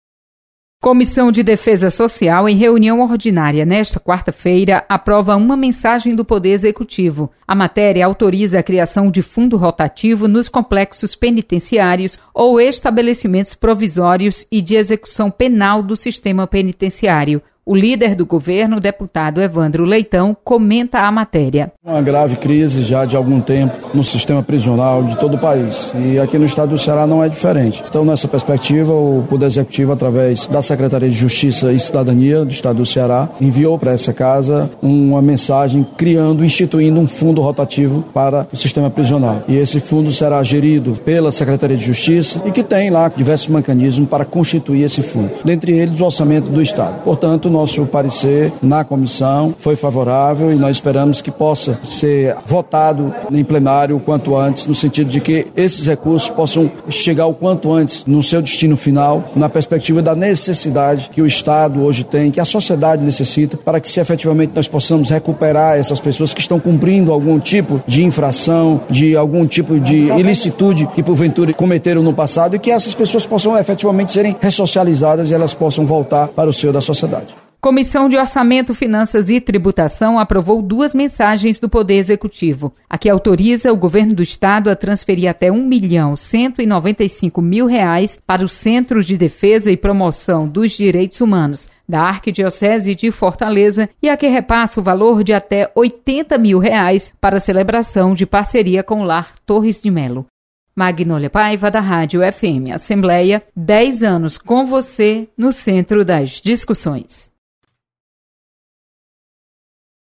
Você está aqui: Início Comunicação Rádio FM Assembleia Notícias Comissão